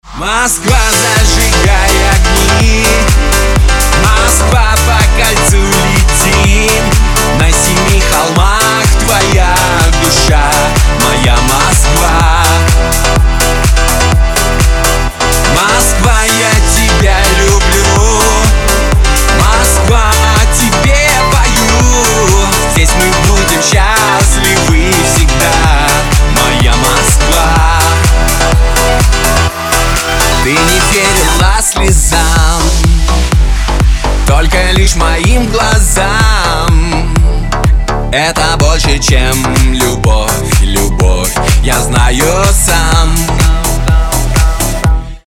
поп
мужской вокал
dance